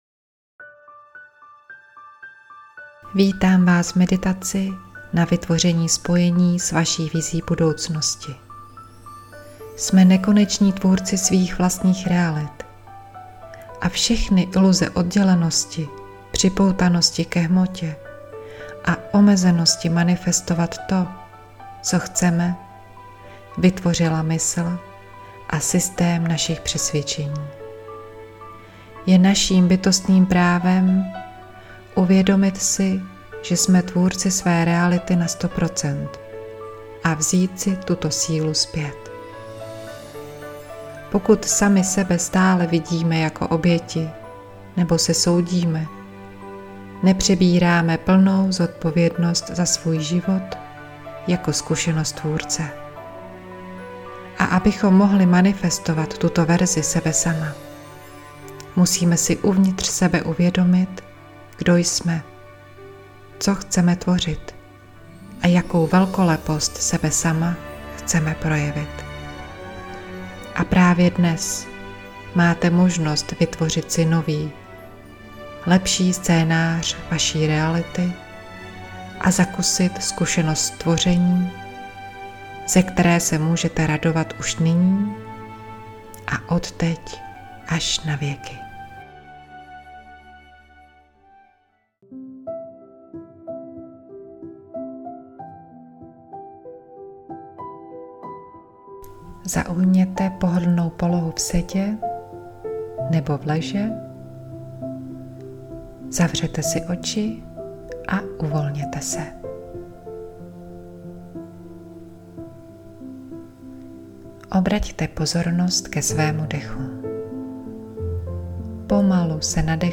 meditace-manifestace-budoucnosti.mp3